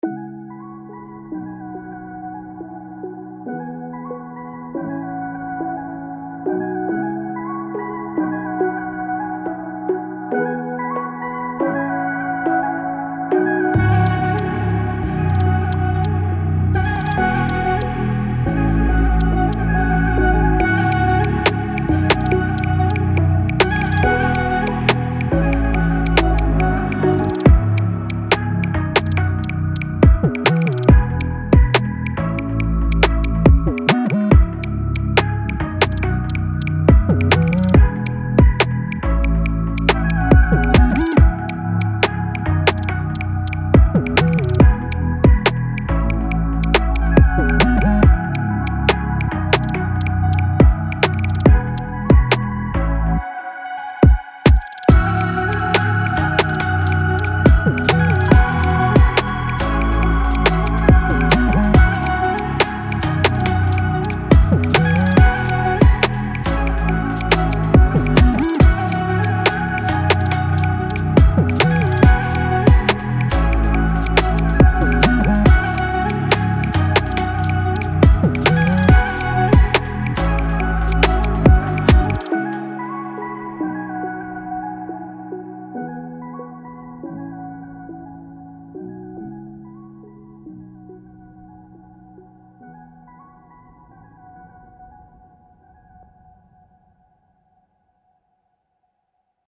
Hip-Hop, Trap, R&B, Pop or even cinematic scoring.